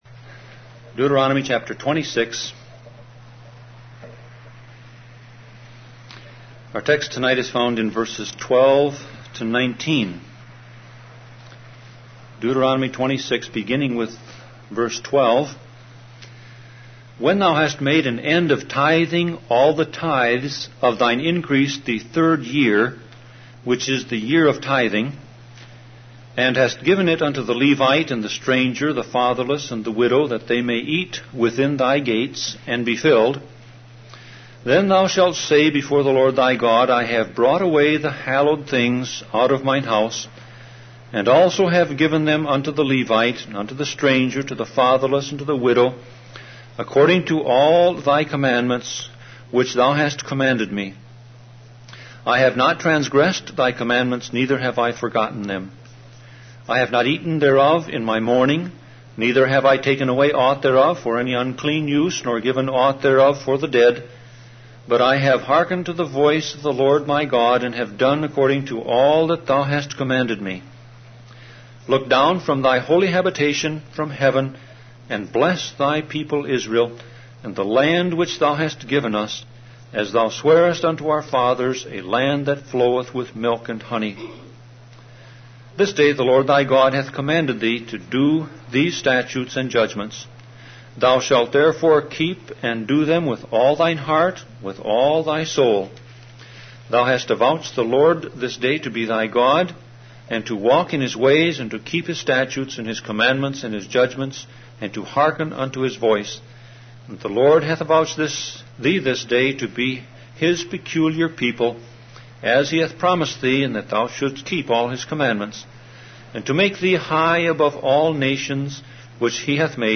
Series: Sermon Audio Passage: Deuteronomy 26:12-19 Service Type